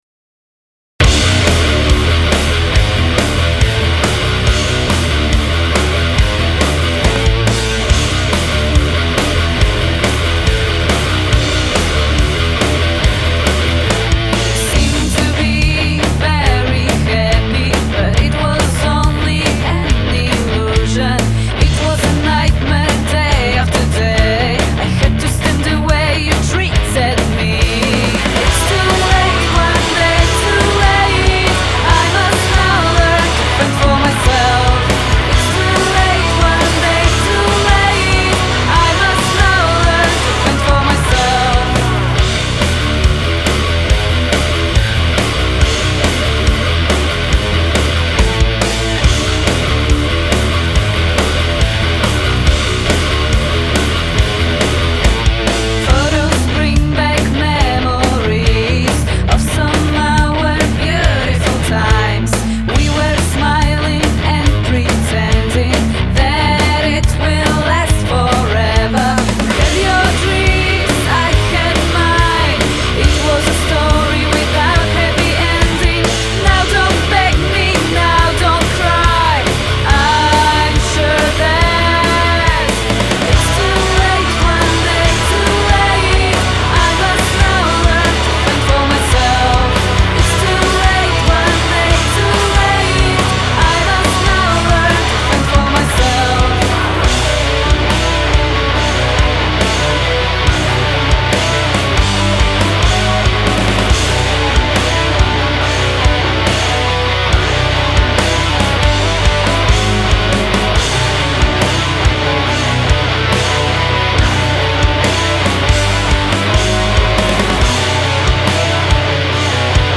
Gatunek: Rock
Vocals
Guitars
Bass
Drums